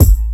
Kick 23.wav